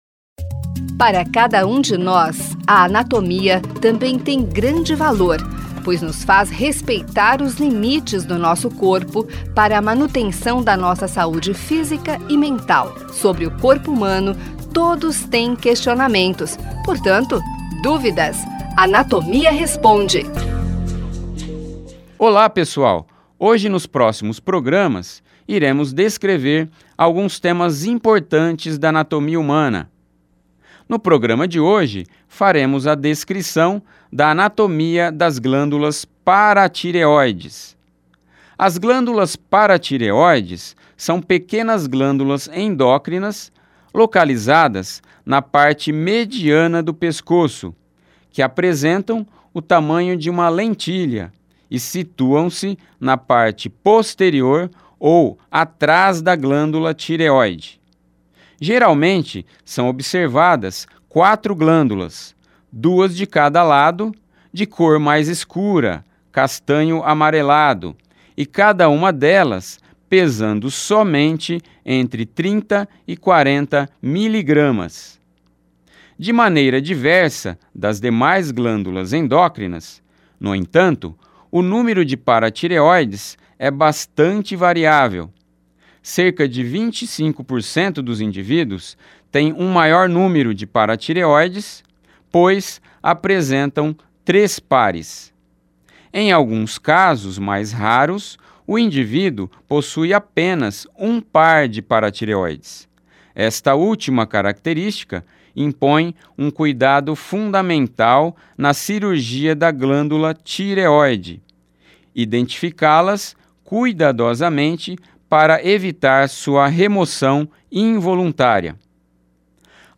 Ouça na íntegra o boletim do Anatomia Responde .